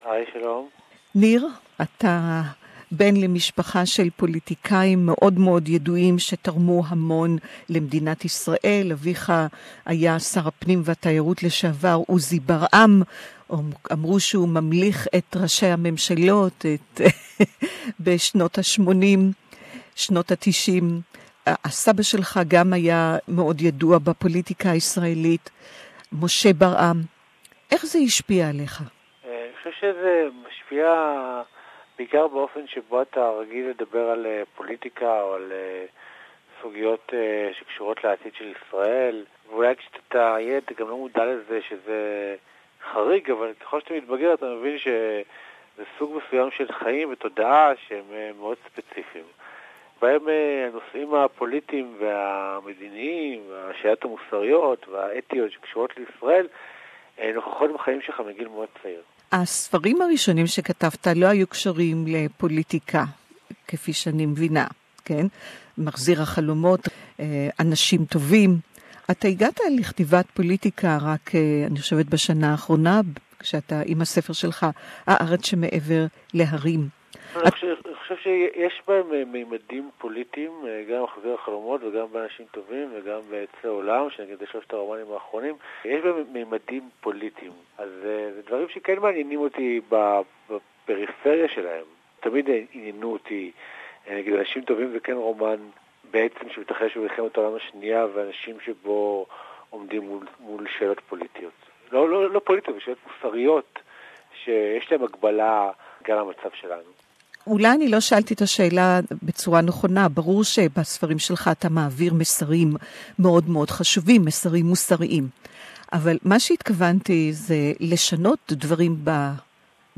Nir Baram, A unique journey of an Israeli in the West Bank... Hebrew Interview